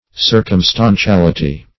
Circumstantiality \Cir`cum*stan`ti*al"i*ty\, n.